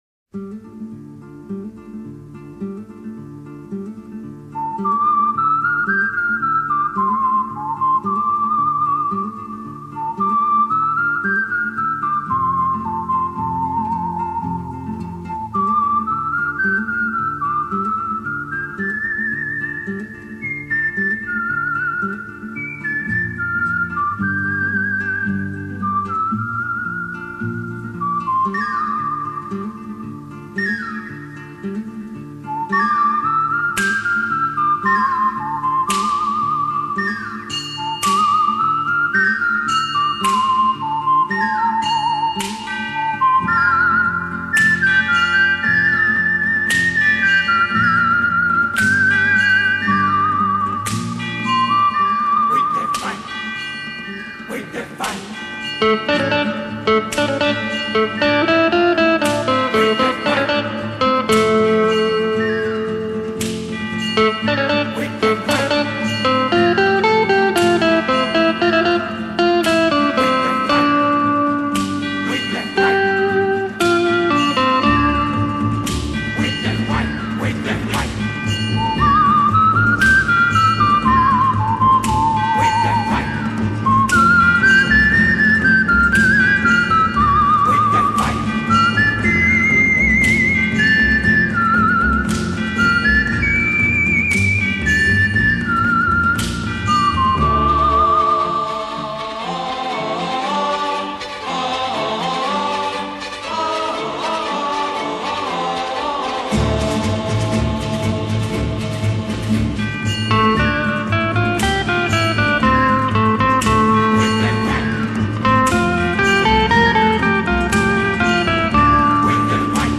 Soundtrack, Western, Score